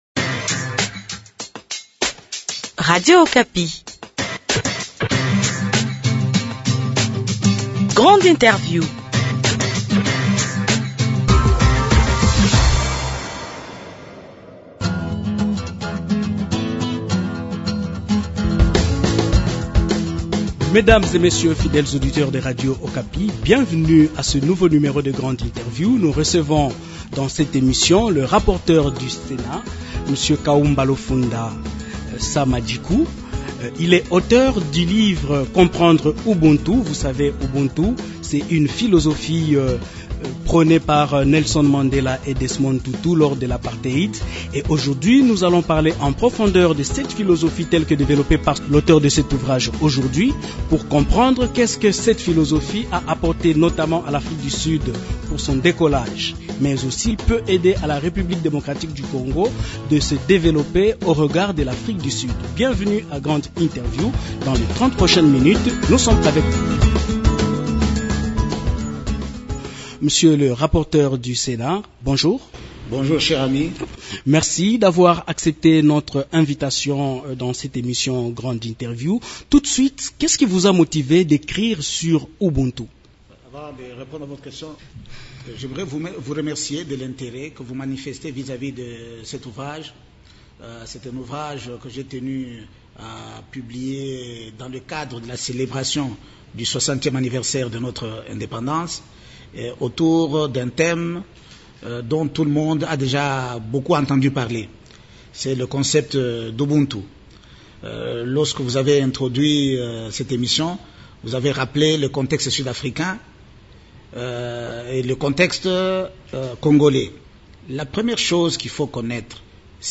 Grande interview de ce jour reçoit le rapporteur du sénat, le professeur Kaumba Lufunda. Il est auteur du livre « comprendre Ubuntu. » dans cet ouvrage paru aux éditions de l’Harmattan, le sénateur Kaumba Lufunda parle du pardon et de la réconciliation nationale comme gage pour le développement de toute l’Afrique, a l’instar de l’Afrique du Sud après l’apartheid.